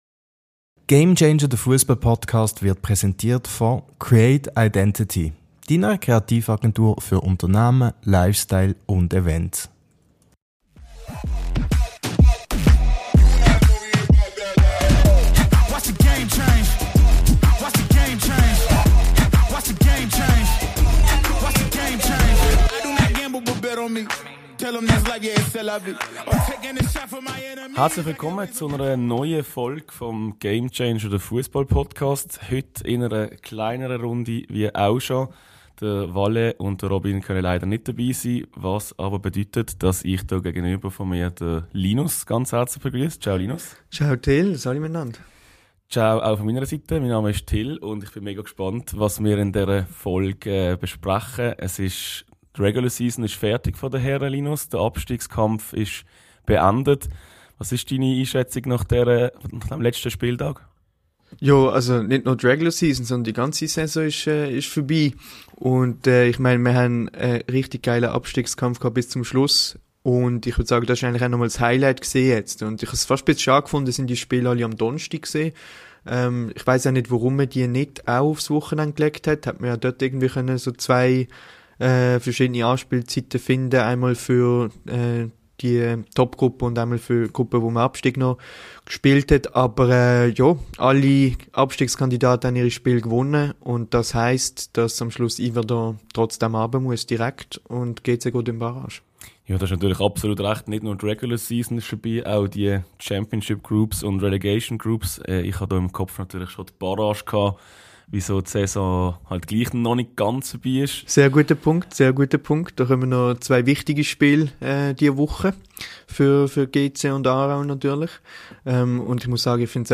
Viel Spass mit Folge 53 🫶 Disclaimer: Im zweiten Teil der Folge hatten wir leider einige technische Probleme. Daher ist der Ton an einigen Stellen nicht optimal.